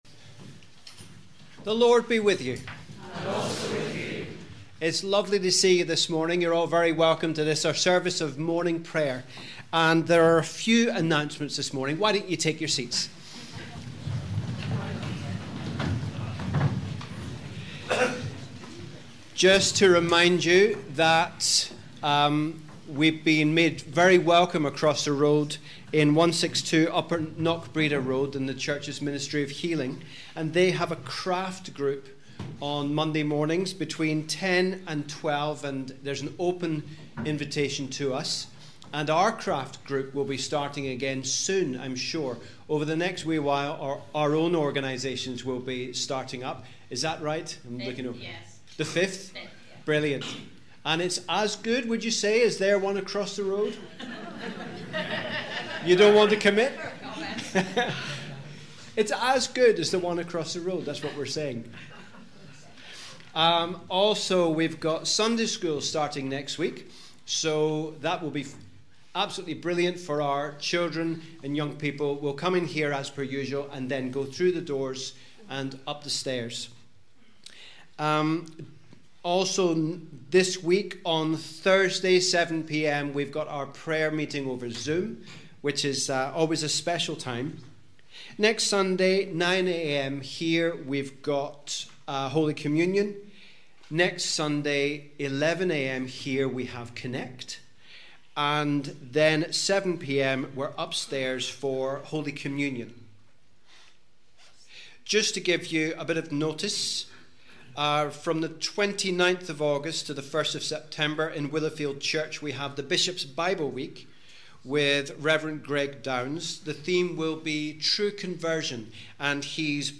We warmly welcome you to our service of Morning Prayer for the 12th Sunday after Trinity.